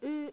u as in rule